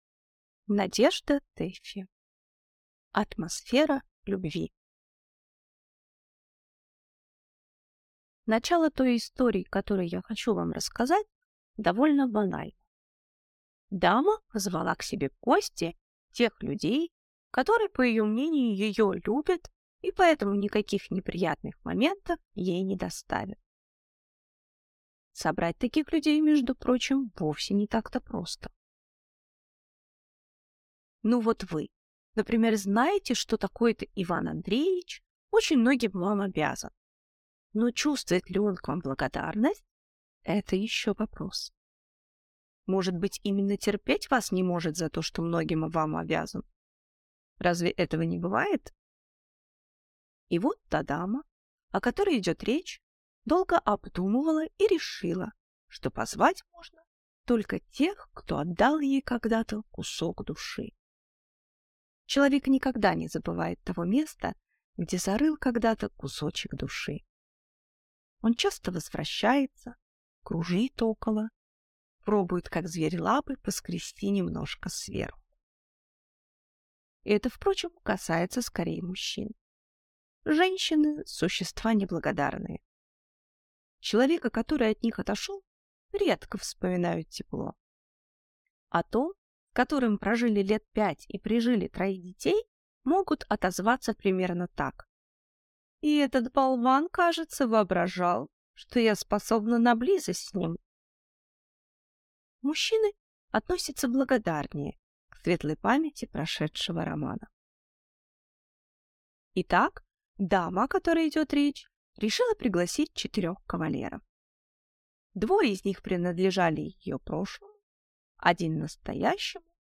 Аудиокнига Атмосфера любви | Библиотека аудиокниг